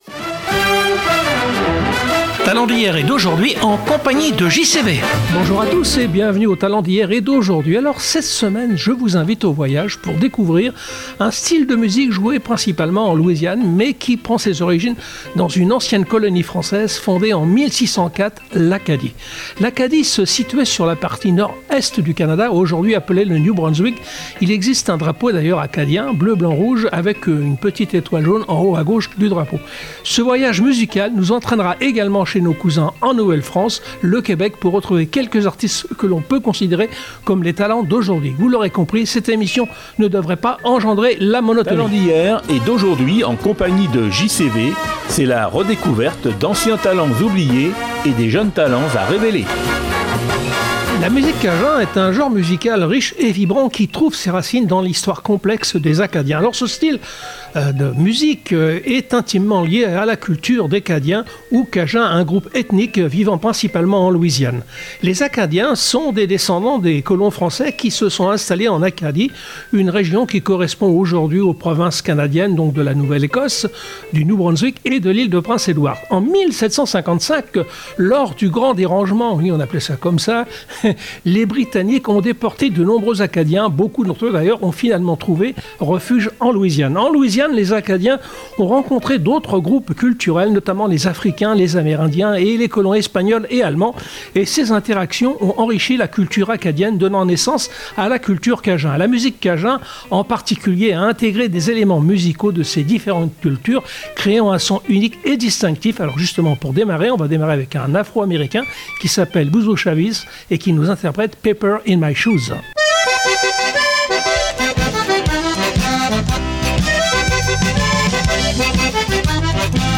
cajun zydeco-01.
30-talent-musique-cajun.mp3